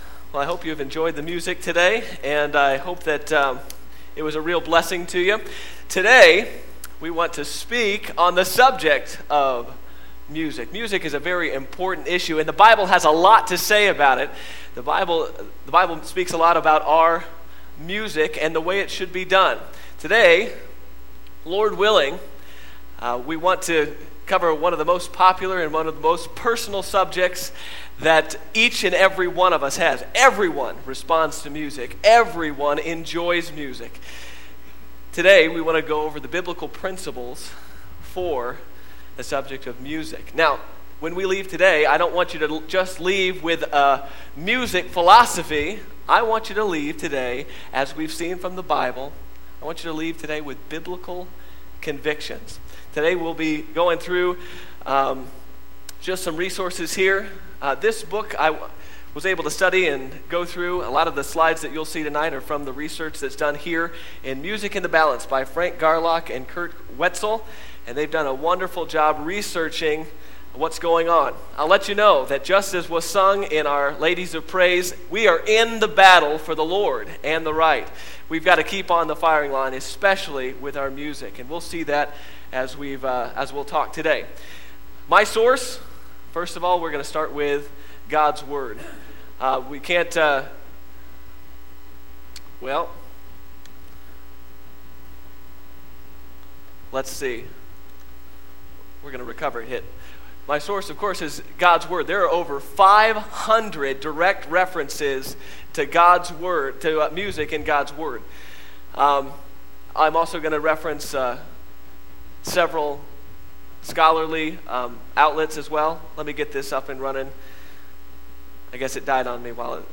Sermon Audio - Media of Worth Baptist Church